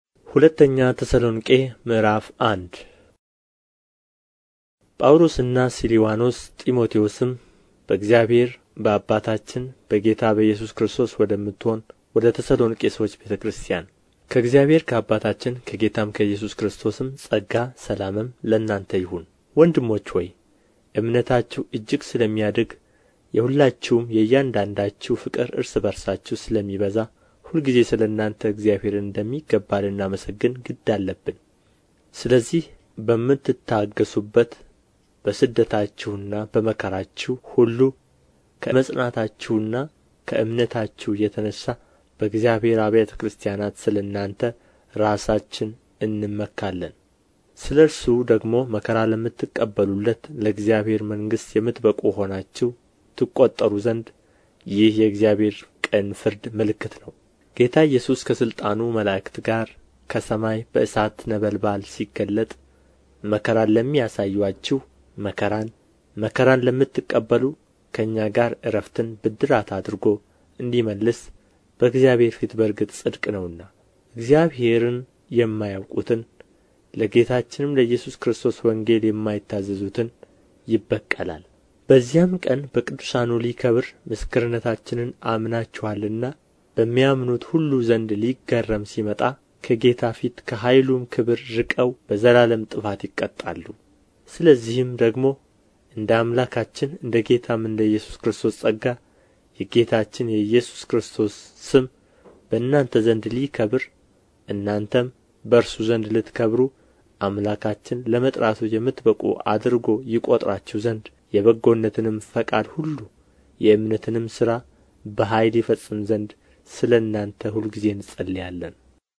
ምዕራፍ 1 ንባብ